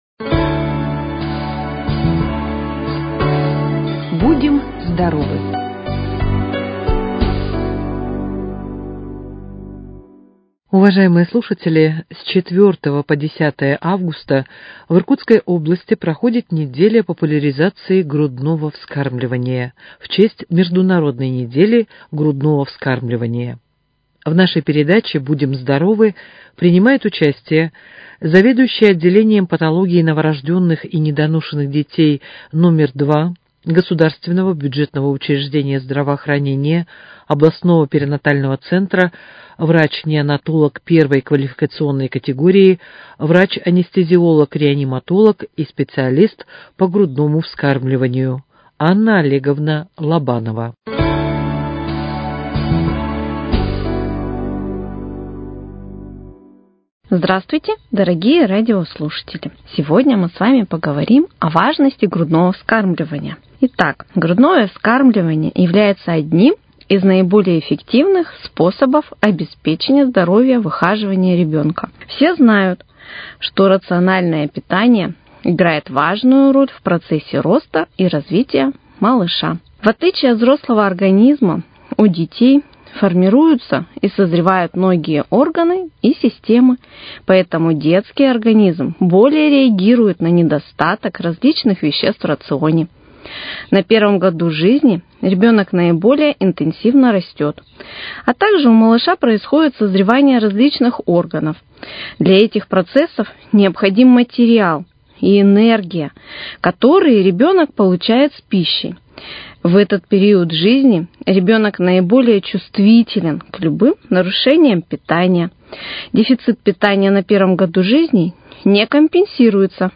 Предлагаем вашему вниманию очередную передачу из серии, которая готовится совместно с областным государственным бюджетным учреждением здравоохранения «Иркутский областной центр общественного здоровья и медицинской профилактики».